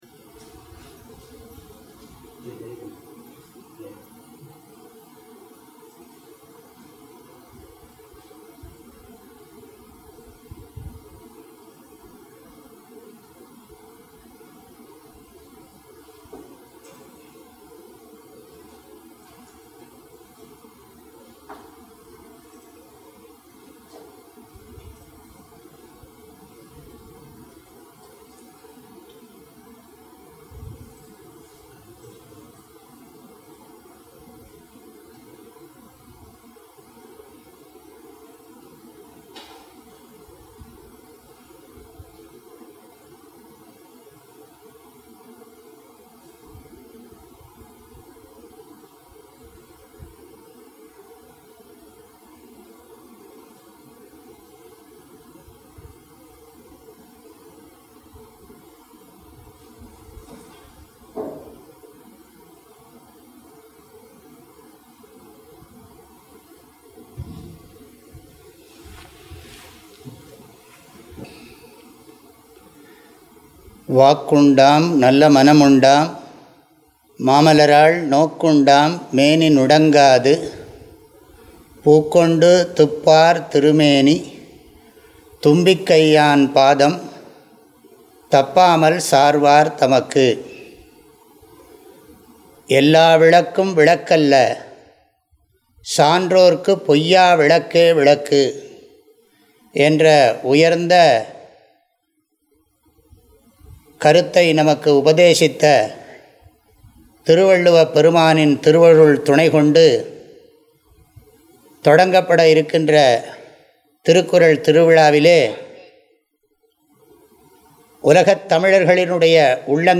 திருக்குறள் முற்றோதல் ஒரு நூலை முழுவதுமாக ஓதிப் பாராயணம் செய்வதற்கு முற்றோதல் என்று பெயர். திருக்குறளில் உள்ள அறத்துப்பால், பொருட்பால், காமத்துப்பால் ஆகியவற்றில் உள்ள 1330 குறட்பாக்களையும் முழுமையாக ஓதுதல் திருக்குறள் முற்றோதல் எனப்படும்.